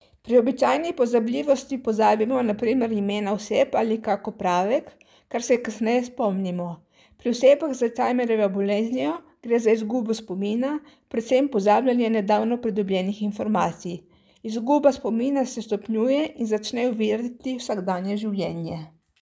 Tonske izjave: